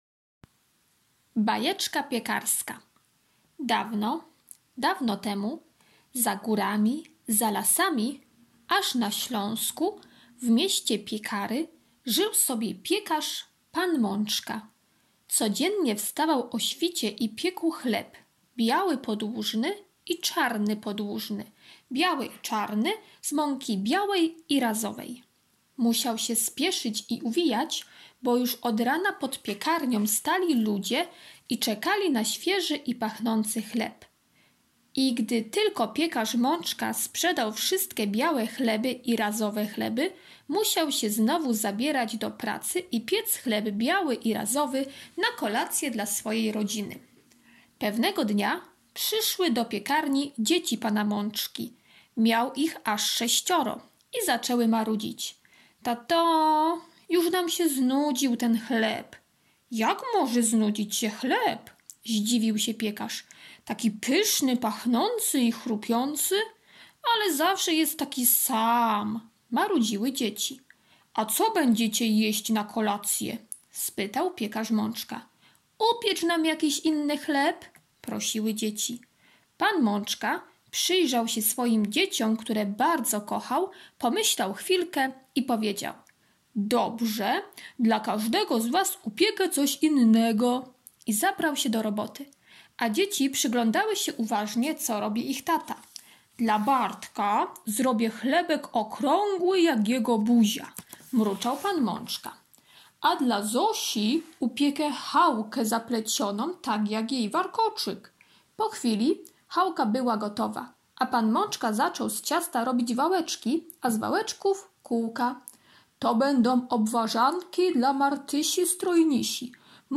czwartek - opowiadanie o piekarzu [6.59 MB] czwartek - ćw. dla chętnych - kolorowanka [400.00 kB] czwartek - ćw. dla chętnych - litera K, k [118.25 kB]